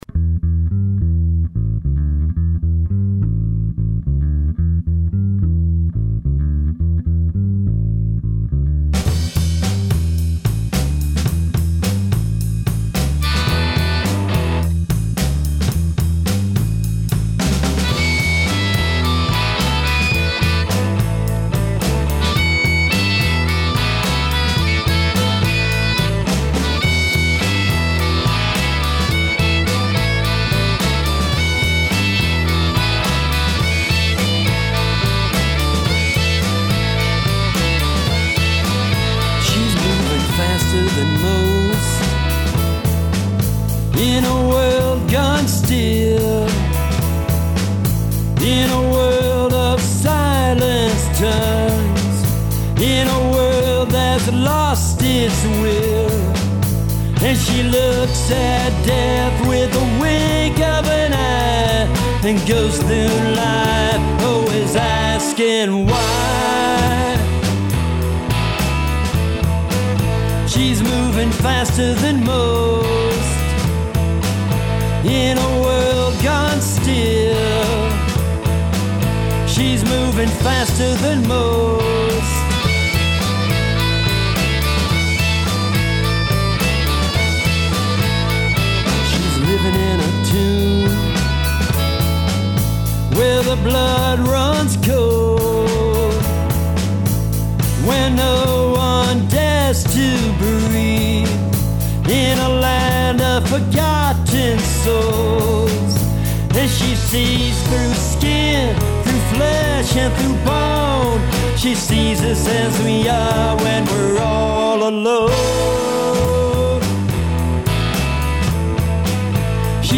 Theremin Stays! were recorded in Chicago at Handwritten